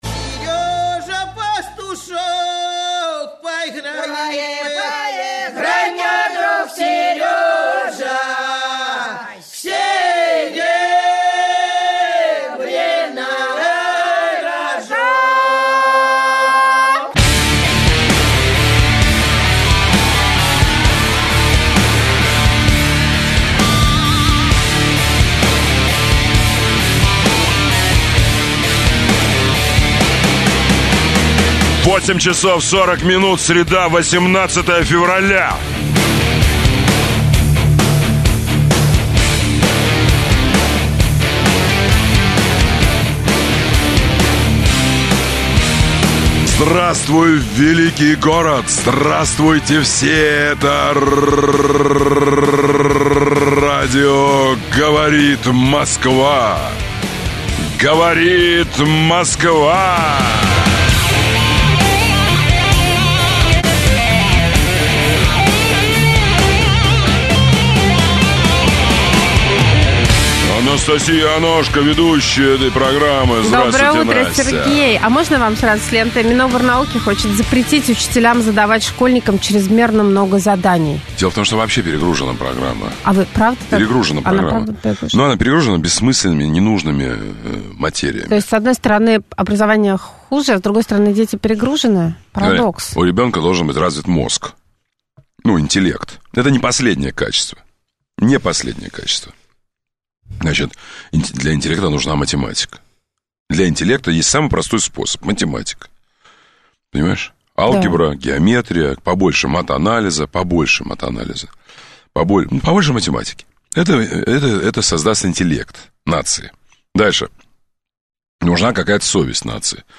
Авторская программа Сергея Доренко. Обсуждение самых актуальных общественно-политических тем, телефонные голосования среди слушателей по самым неоднозначным и острым вопросам, обзоры свежей прессы.